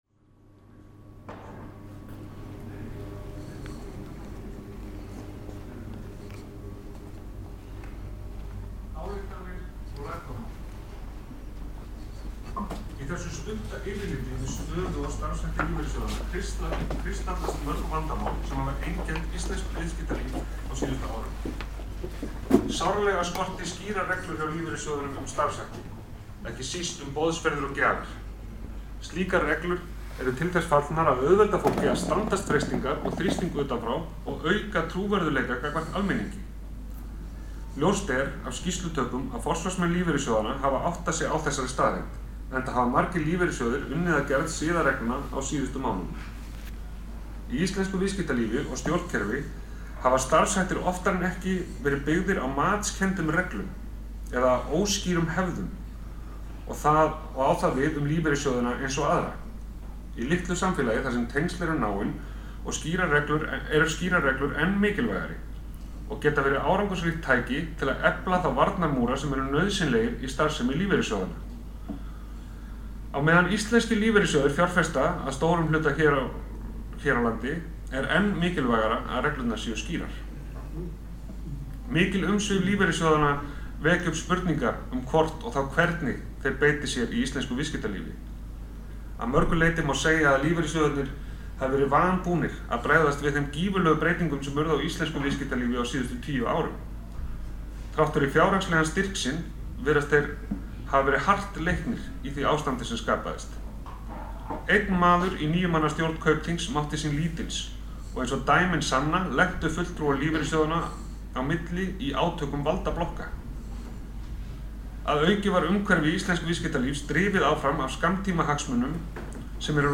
Lýsir hún ótrúlega vel afglöpum, fávitaskap og stjórnleysi sem og græðgi íslenskra broddborgara. Voru það leikarar í Borgarleikhúsinu sem tóku að sér þennan lestur.
Ég mætti í Borgarleikhúsið á laugardagskvöldi u.þ.b. 6 klukkustundum áður en lestri lauk. Nokkrir áheyrendur voru á staðnum og má heyra þá flissa á völdum stöðum í lestrinum og vera á rápi. Þá má heyra í bakgrunni tónlist frá yfirstandandi leiksýningu.
Upplesturinn er fremur gjallandi. Stafar það af því að lesturinn var magnaður fram í hátalara. Hefst hljóðmyndin á því að ég geng utan frá andyri leikhússins inn í herbergið þar sem lesturinn fór framm.
Tekið var upp á Olympus LS10 í 24bit/44Khz. Hljóðnemar voru MMaudio binaural.